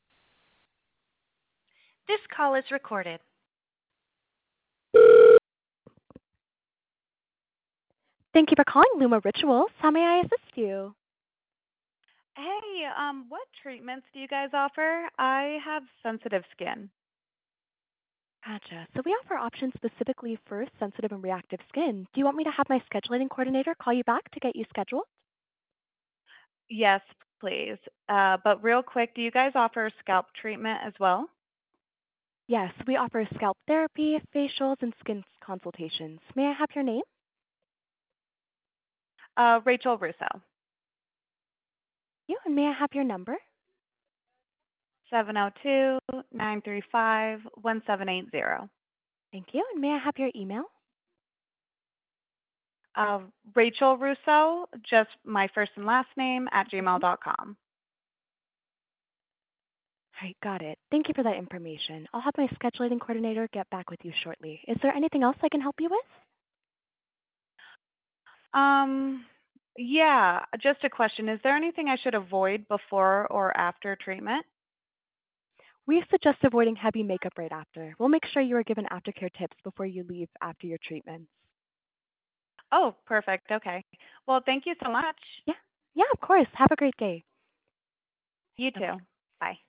Listen to a demo to hear Abby’s virtual receptionist taking real calls like yours!
HUMAN RECEPTIONIST